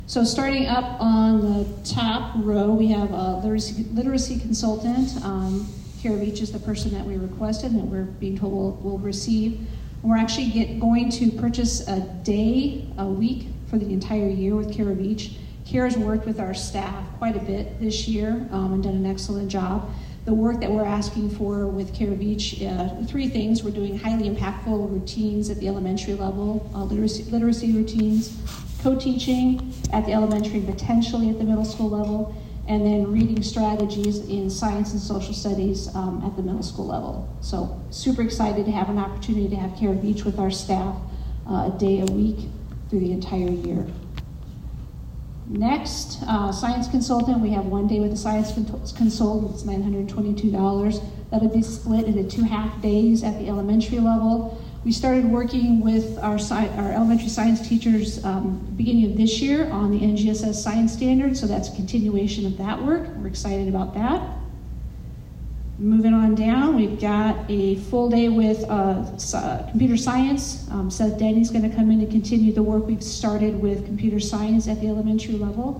The local school board held a budget public hearing to discuss the proposed fiscal year 2026 tax levy.